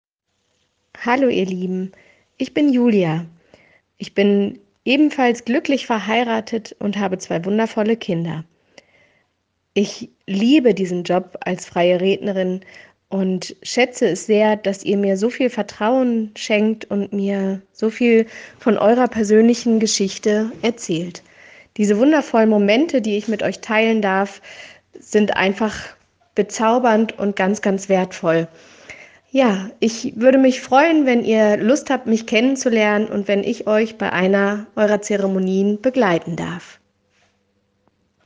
Stimmprobe